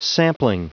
Prononciation du mot sampling en anglais (fichier audio)
Prononciation du mot : sampling